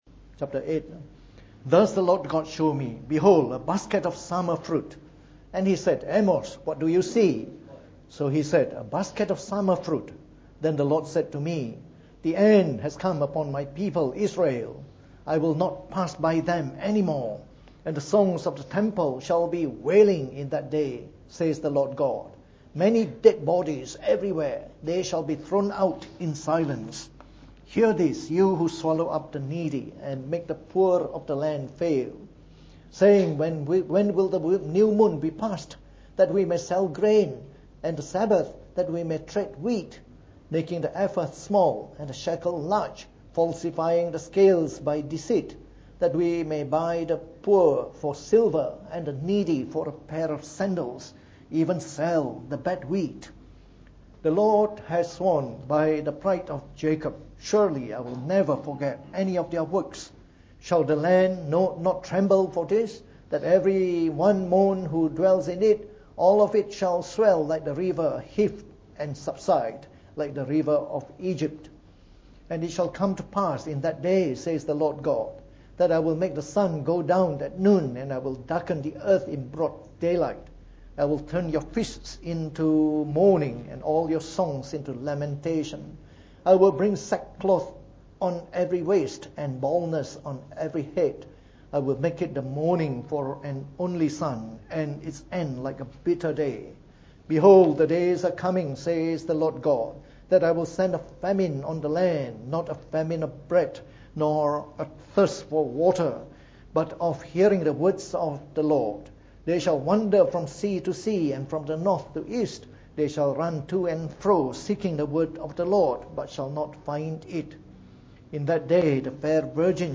From our series on the Book of Amos delivered in the Morning Service.